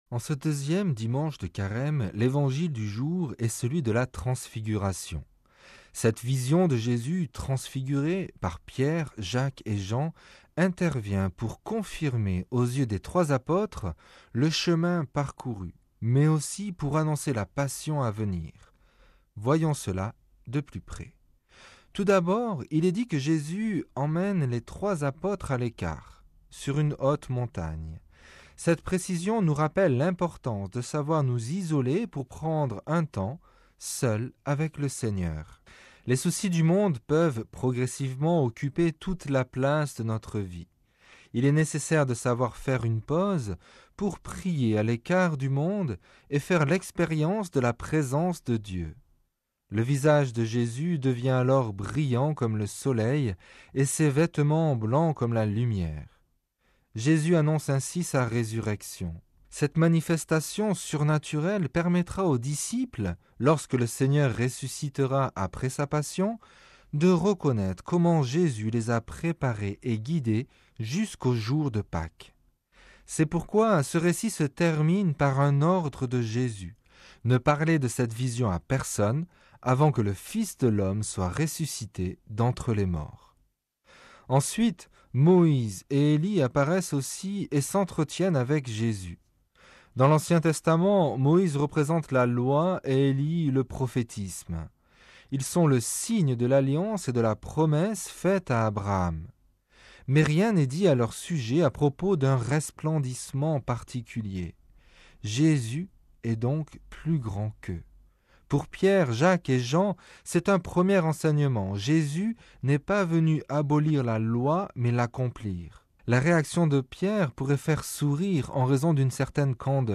Commentaire de l'Evangile du dimanche 16 mars